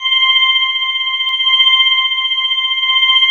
90 ORGAN  -R.wav